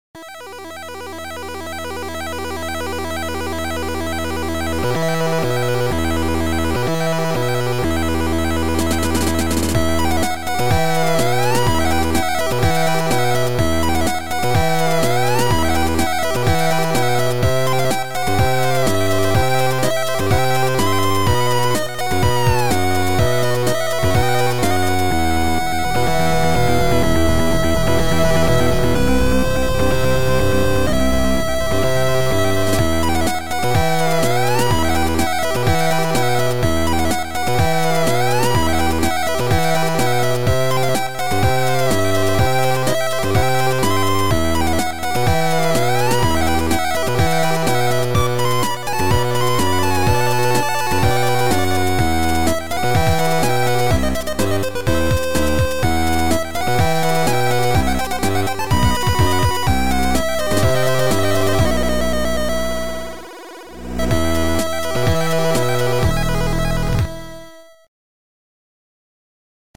Chip Music Pack